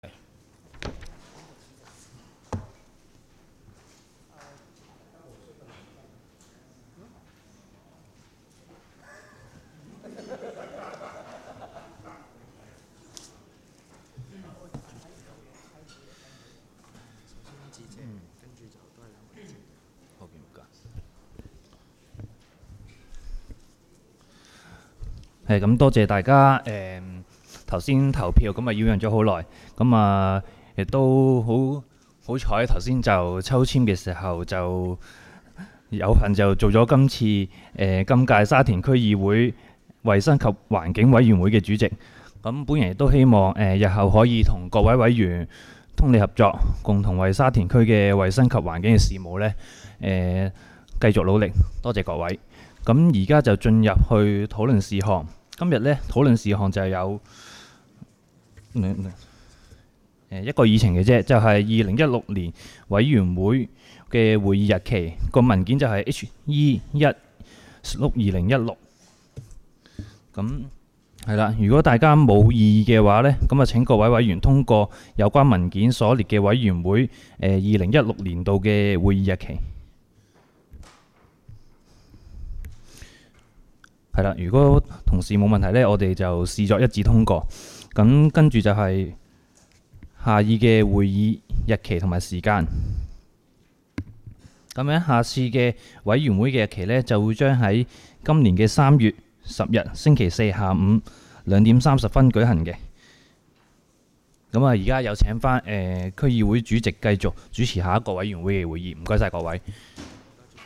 委员会会议的录音记录
地点: 沙田区议会会议室